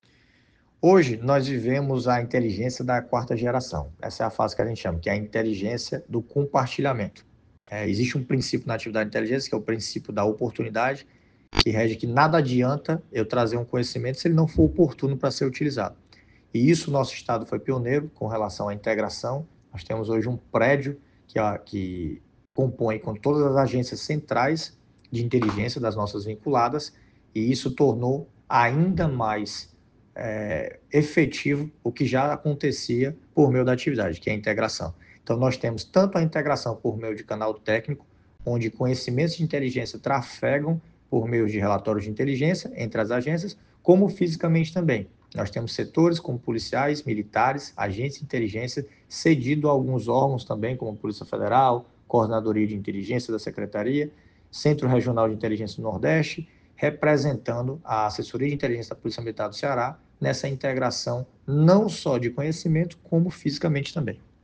Em entrevista por áudio à Assessoria de Comunicação da PMCE